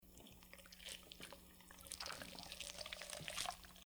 Slime Noises
Made a lot of sounds around what a gelatinous cube or sentient slime might sound like.
slime_move2b.mp3